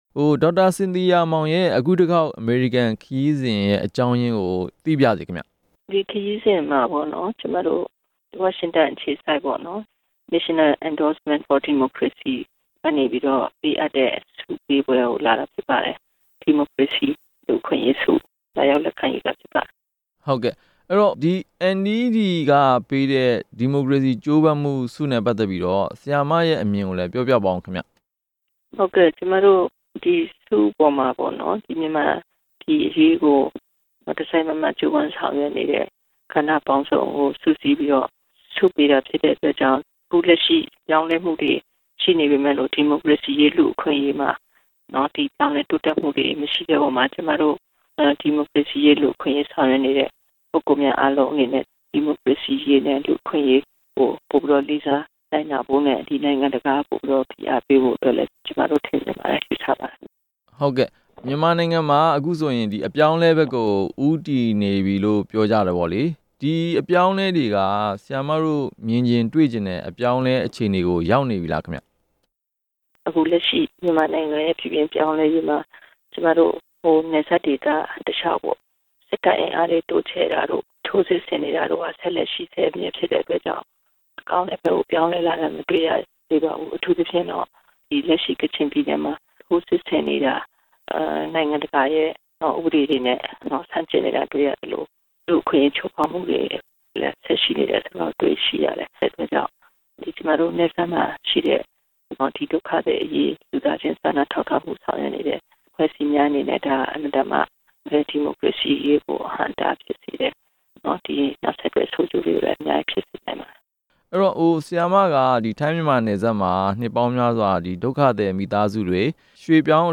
လူ့အခွင့်အရေးဆုရယူမယ့် ဒေါက်တာ စင်သီယာမောင်နှင့် မေးမြန်းခြင်း